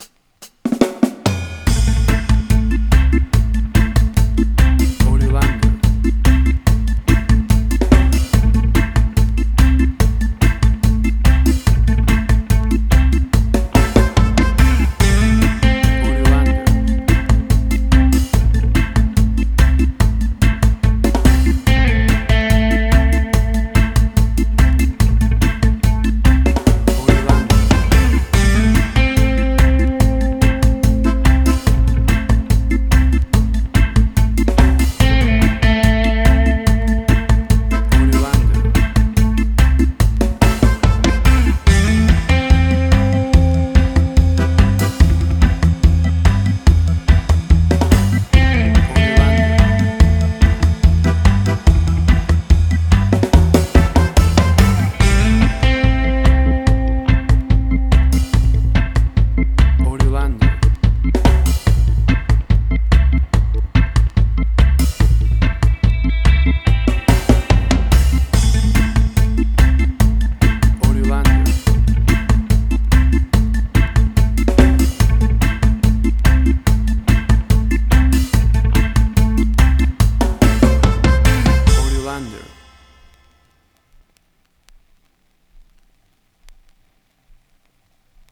Reggae caribbean Dub Roots
Tempo (BPM): 72